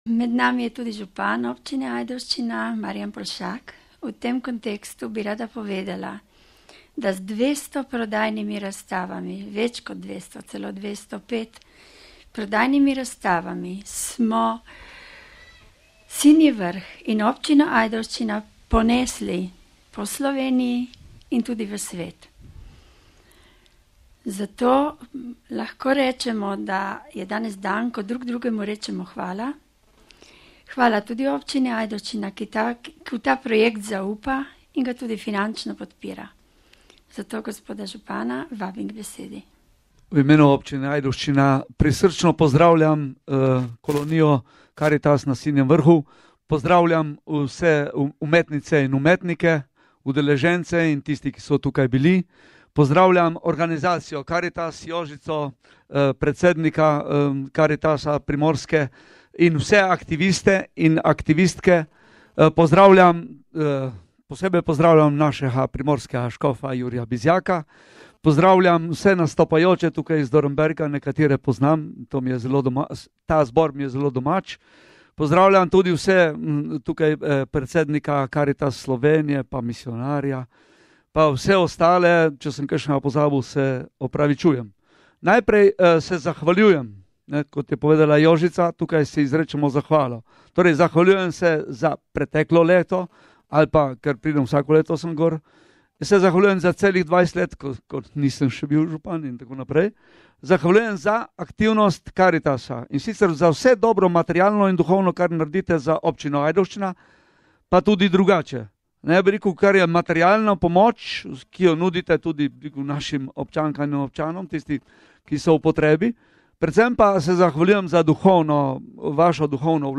Ob 18. uri je bila sklepna prireditev s predstavitvijo nastalih del in kulturnim programom.
Audio nagovora župana Marjana Poljšaka: RealAudio